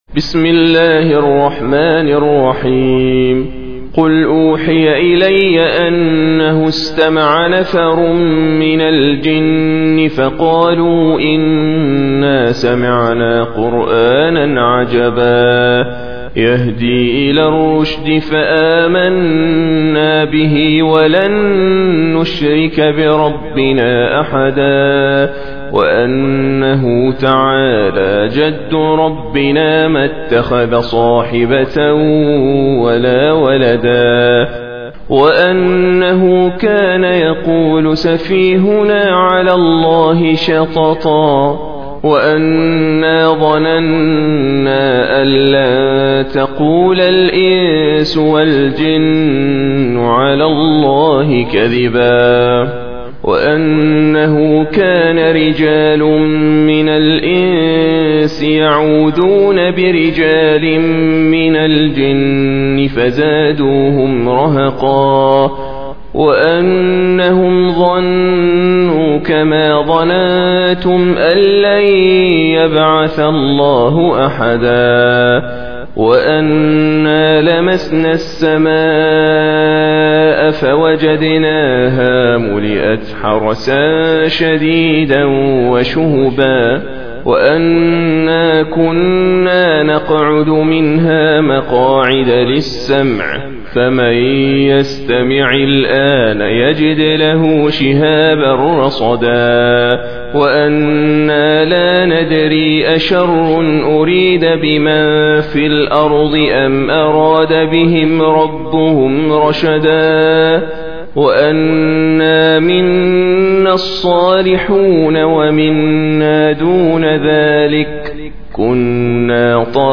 Surah Sequence تتابع السورة Download Surah حمّل السورة Reciting Murattalah Audio for 72. Surah Al-Jinn سورة الجن N.B *Surah Includes Al-Basmalah Reciters Sequents تتابع التلاوات Reciters Repeats تكرار التلاوات